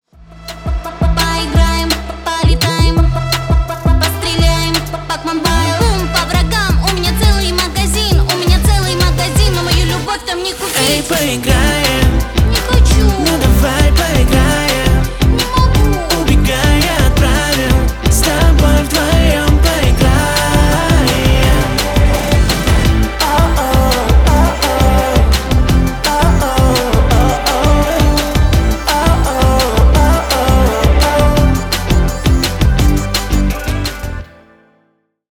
Танцевальные # милые